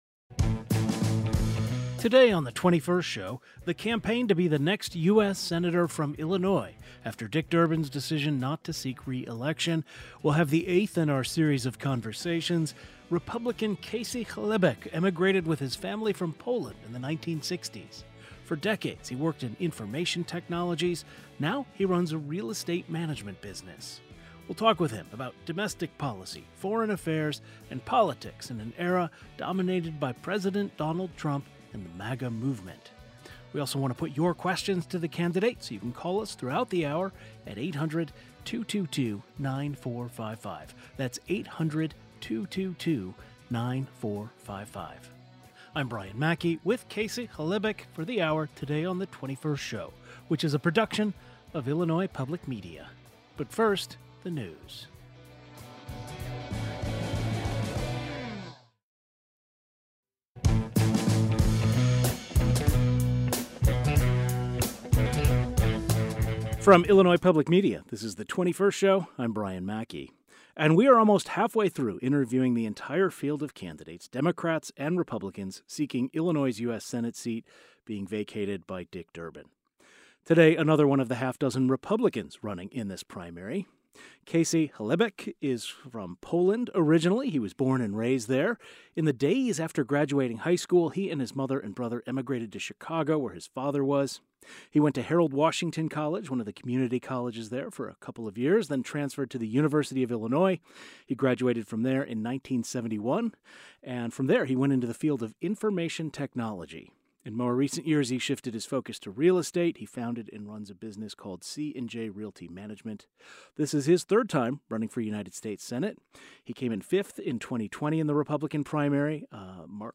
joins the program from our studio in Urbana